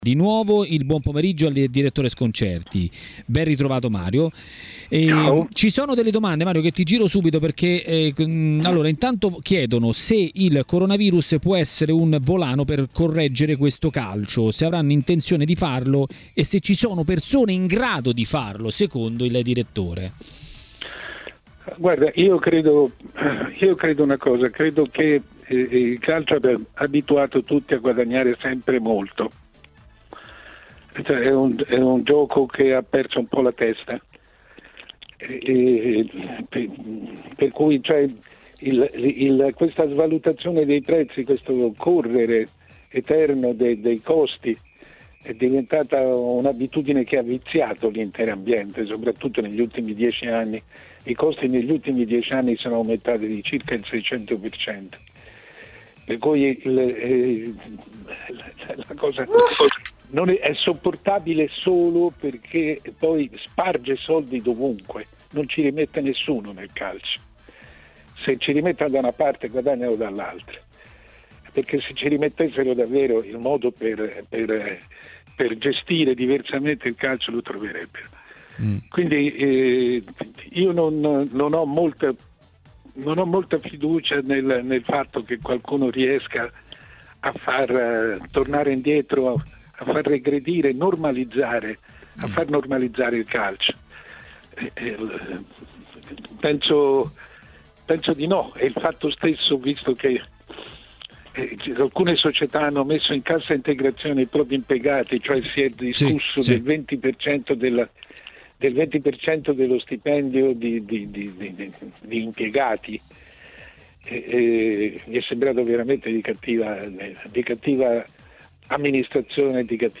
è intervenuto a TMW Radio, durante Maracanà, per parlare del futuro del calcio italiano.